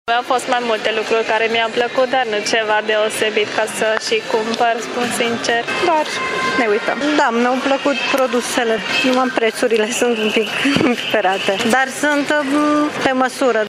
Centrul Tîrgu-Mureșului s-a colorat astăzi cu produse tradiționale din toate colțurile țării.
Pe de altă parte, vizitatorii au admirat, au întrebat de preț și s-au mirat. Ei așteptau ca produsele tradiționale să fie mai ieftine, motiv pentru care s-au orientat mai mult spre lucrurile mici: